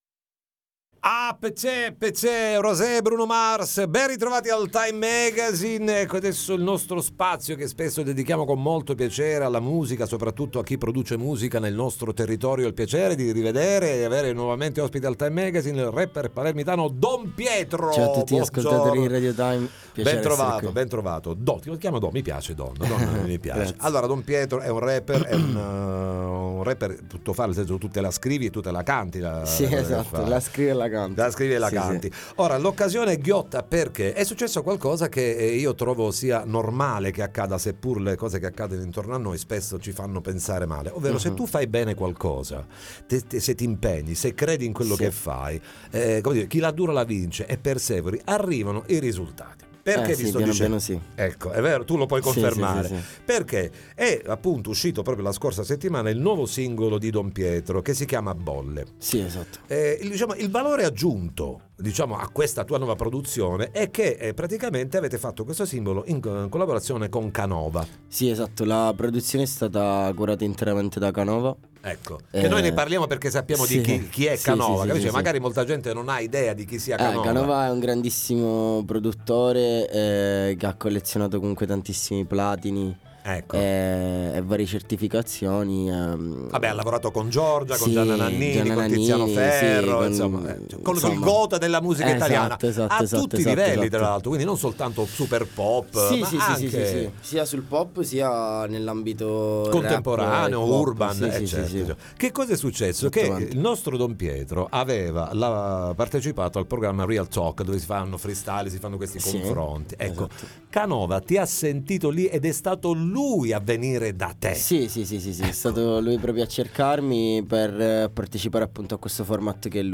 ne parliamo con lui ai ns. microfoni